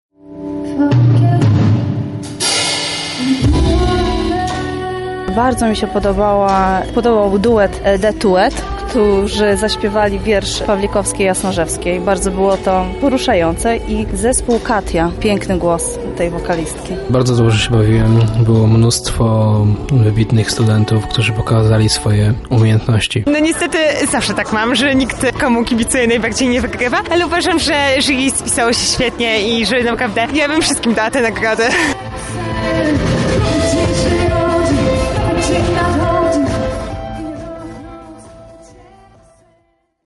Open-Stage-SERWIS_mixdown.mp3